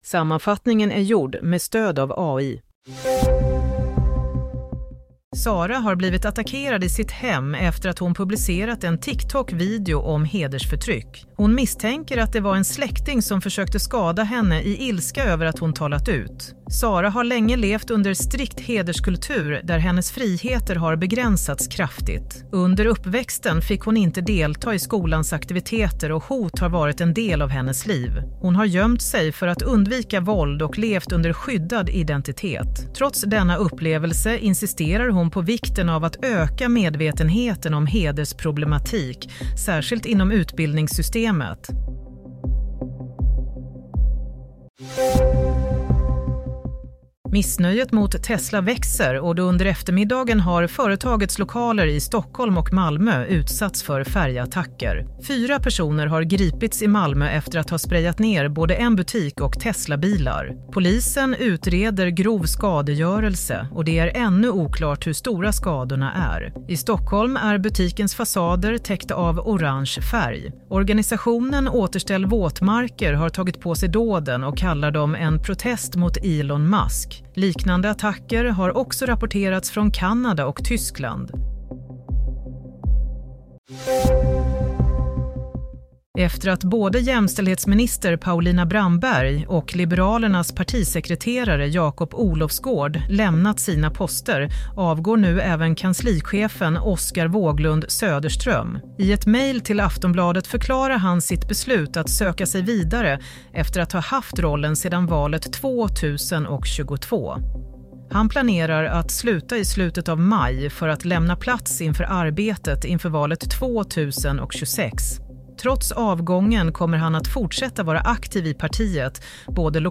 Play - Nyhetssammanfattning - 31 mars 22.30
Sammanfattningen av följande nyheter är gjord med stöd av AI.